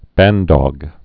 (băndôg, -dŏg)